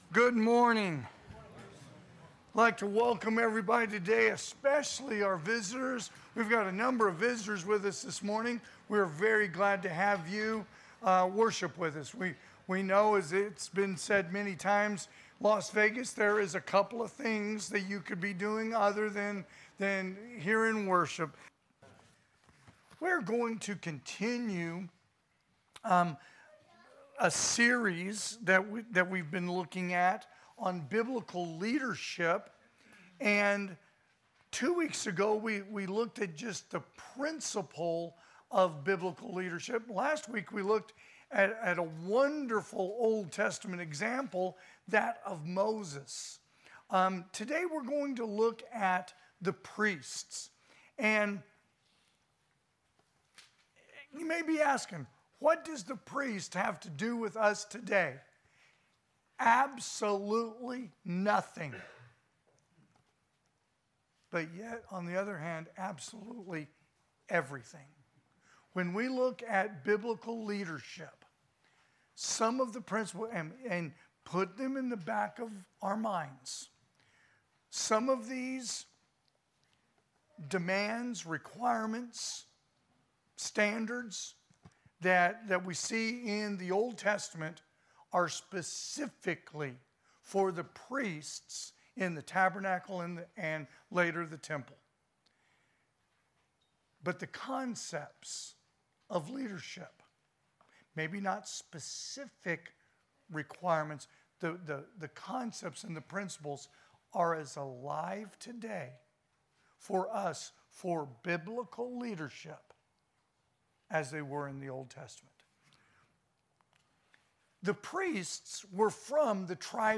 2025 (AM Worship) "Biblical Leadership